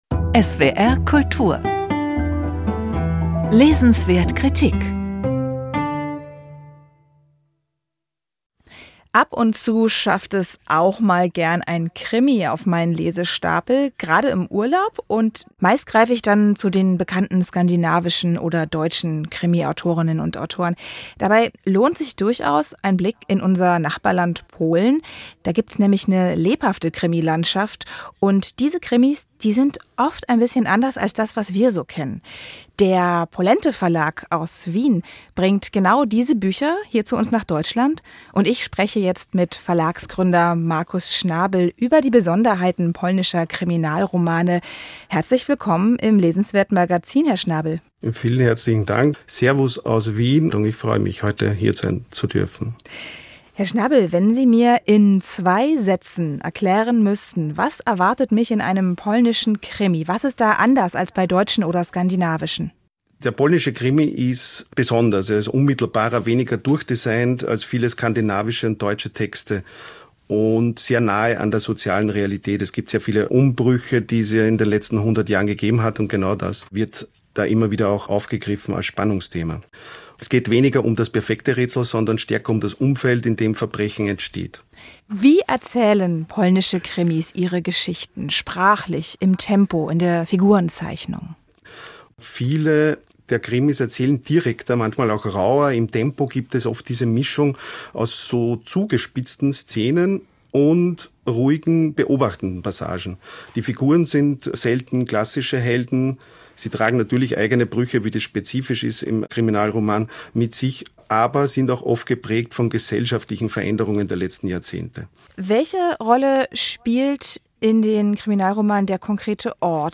Hier finden Sie die Beiträge aus den SWR Kultur Literatursendungen an einem Ort: Die SWR Bestenliste und die SWR Kultur lesenswert Sendungen Feature, Magazin, Kritik und Gespräch.